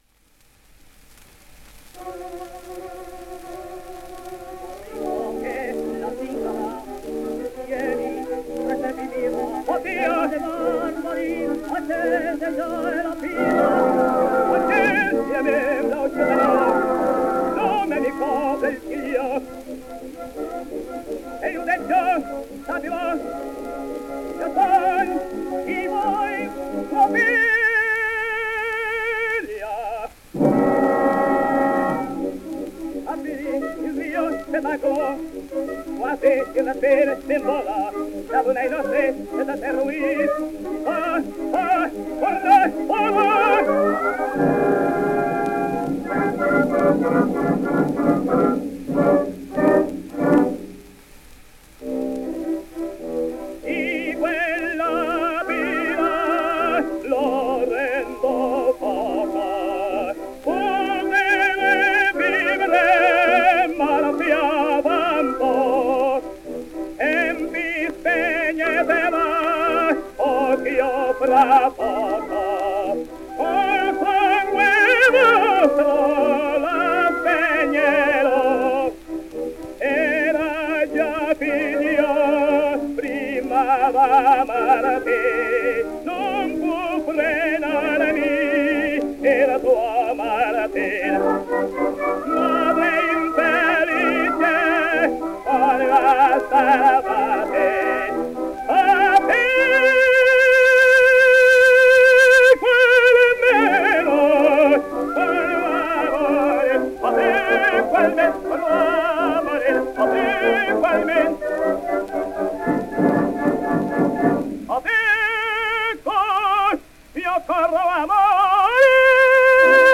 G&T, Milano, fall 1904